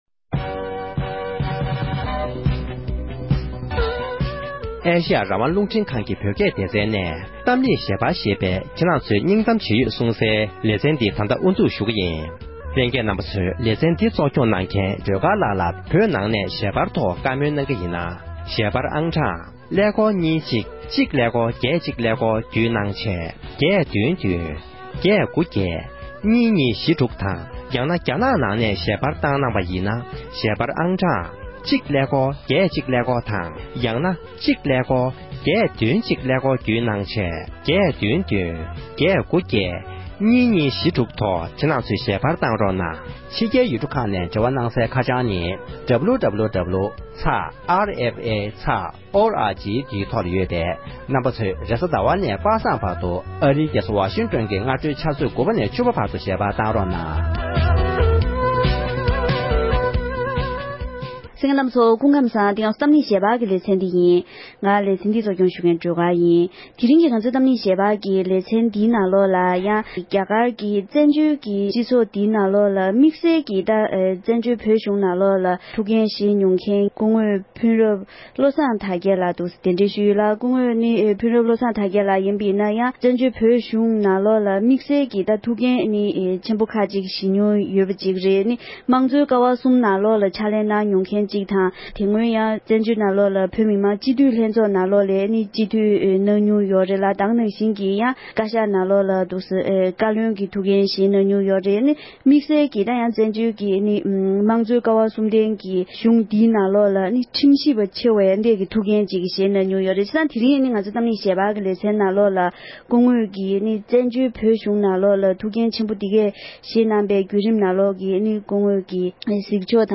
བཙན་བྱོལ་བོད་གཞུང་གི་ཐུགས་འགན་བཞེས་མྱོང་མཁན་གྱི་མི་སྣའི་ལྷན་དུ་གླེང་བ།